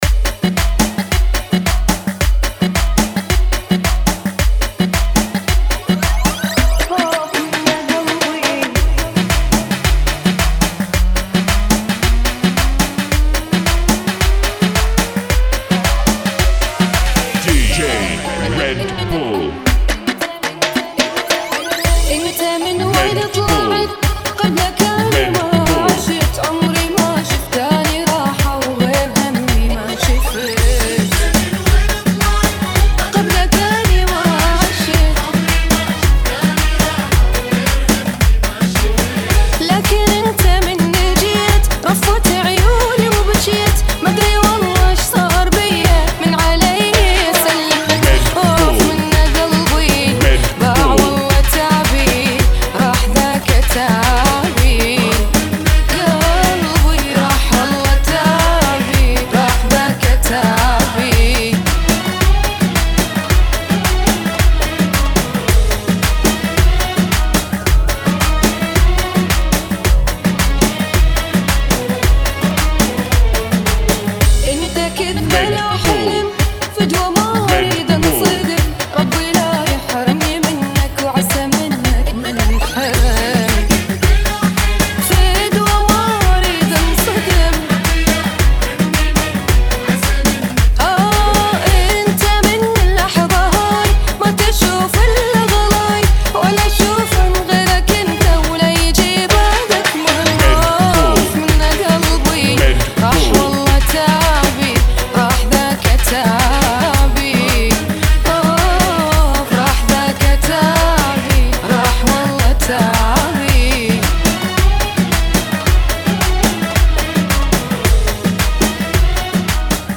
110 bpm